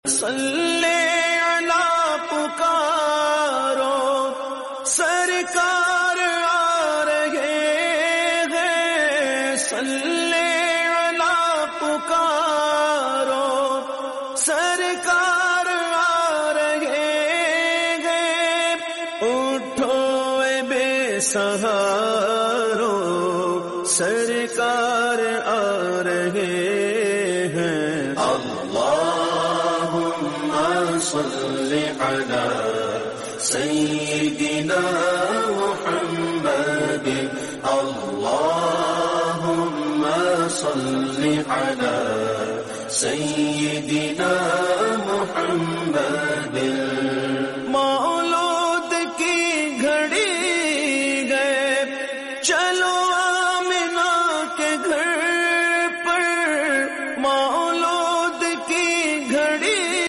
Naat
A heart-touching glimpse from the Rawdah Mubarak